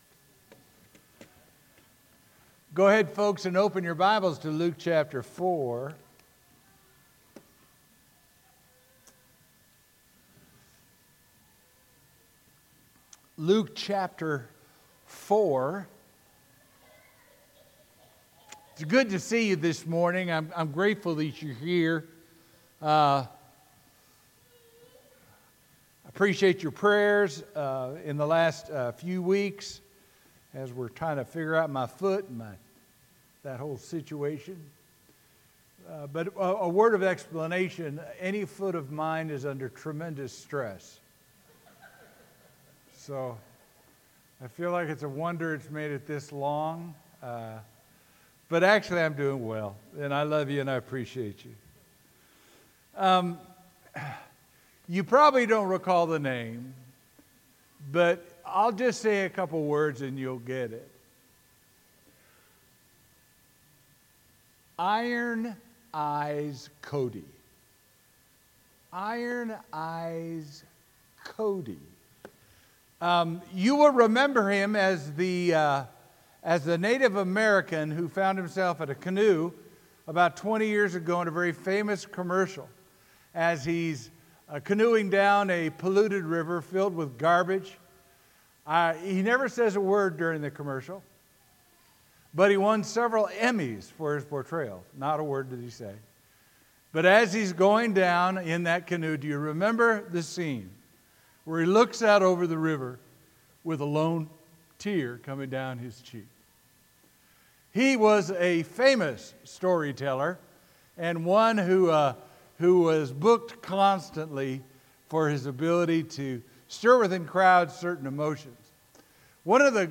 Sermon: Jesus in the Wilderness – IGNITE-TRANSFORM-REFLECT-SHINE